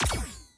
Alien weapon/blast sounds
22khz mono already.
disruptor1_188.wav